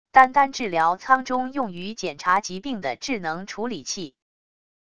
单单治疗舱中用于检查疾病的智能处理器wav音频